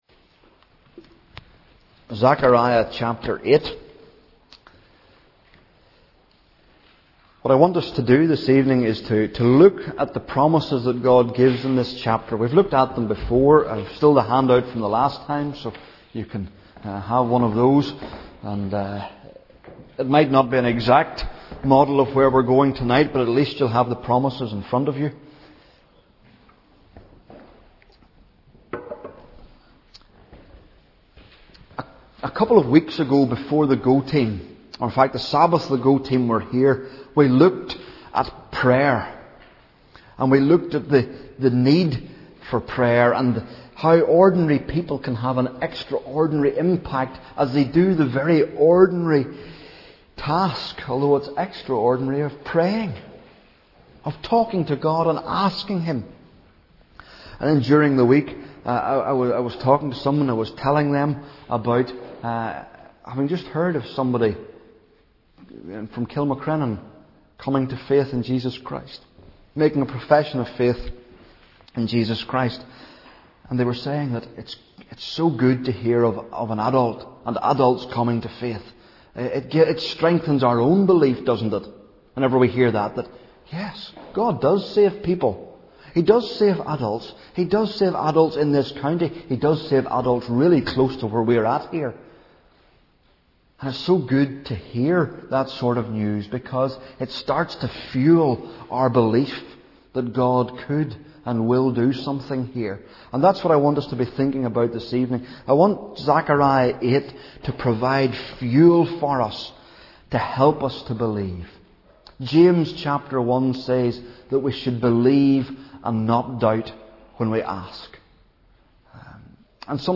Zechariah | Sermons at New Life Fellowship